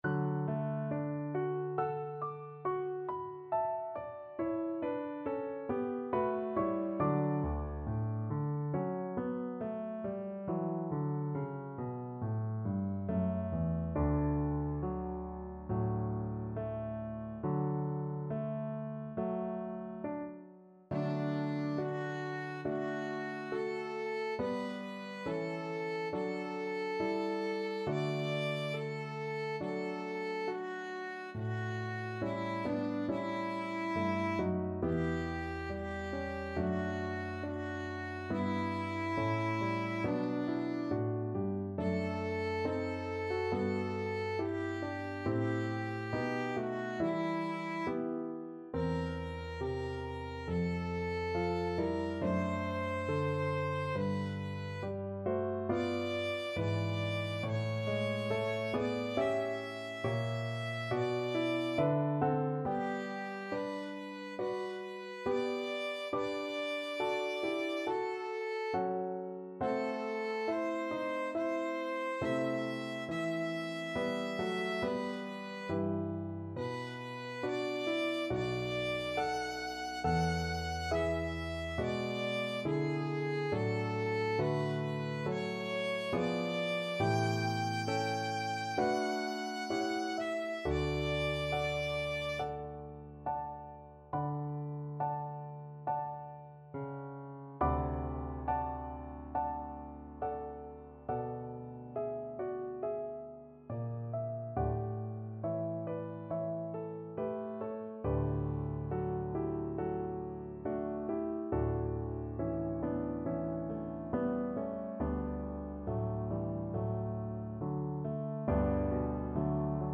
2/2 (View more 2/2 Music)
Slow =c.69
Violin  (View more Easy Violin Music)
Classical (View more Classical Violin Music)